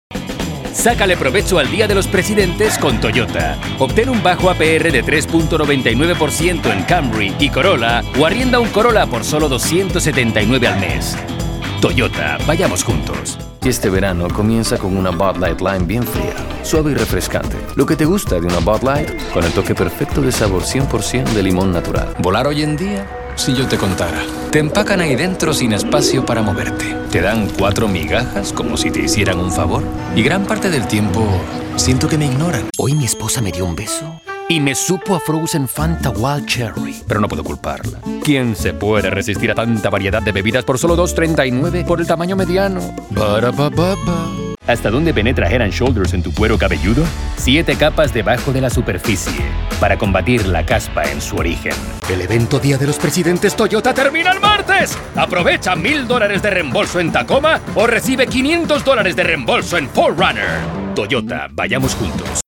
Commercial Demo - Spanish